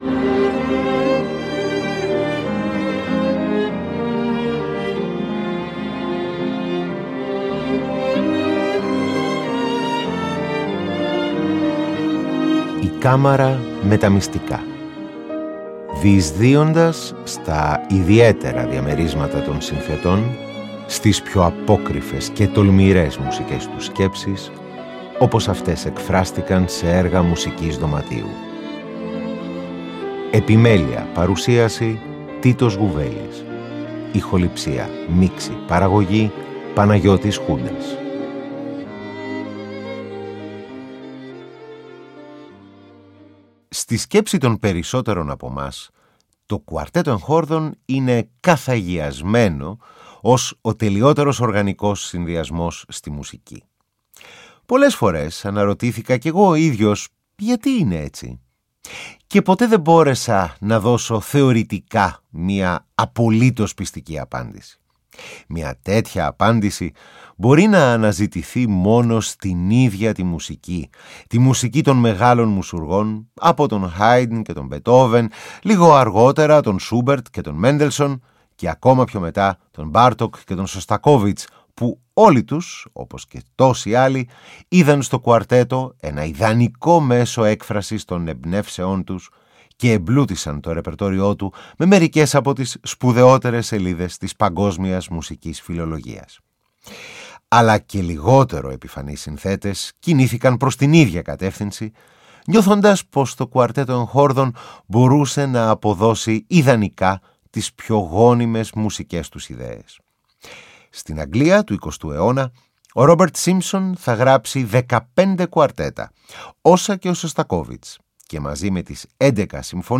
Το αφιέρωμα-ταξίδι της εκπομπής μας στη Bρετανική μουσική δωματίου συνεχίζεται με ένα επιμέρους αφιέρωμα στο είδος του κουαρτέτου εγχόρδων. Ακούγονται αποσπάσματα από το συγκλονιστικό Κουαρτέτο του Edward Elgar και από το άκρως ενδιαφέρον σύγχρονό του Κουαρτέτο του Charles Stanford, καθώς και δύο ριζικά διαφορετικά μεταξύ τους μέρη από το 10ο και το 12ο Κουαρτέτο του παραγνωρισμένου συνθέτη (αλλά διάσημου ραδιοφωνικού παραγωγού στο BBC!) Robert Simpson.